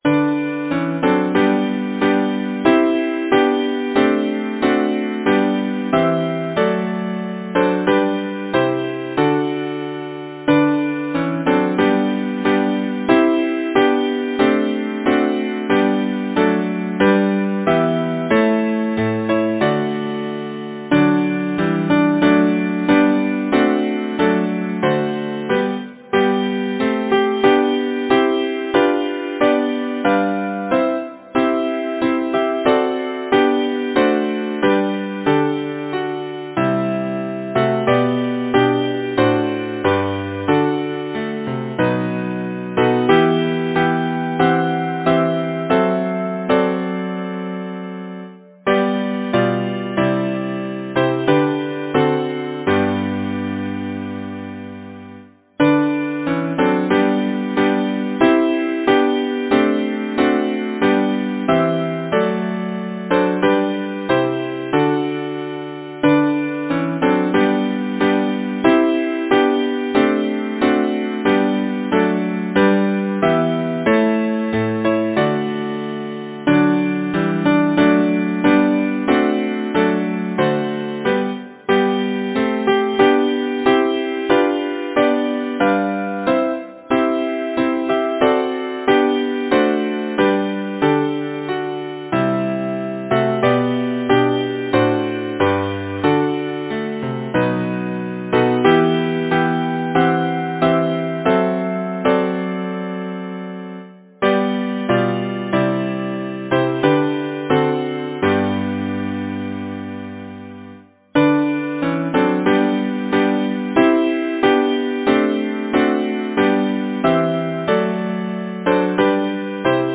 Title: Home is home, however lowly Composer: George M. Garrett Lyricist: Arthur Smith Arnott Number of voices: 4vv Voicing: SATB Genre: Secular, Partsong
Language: English Instruments: A cappella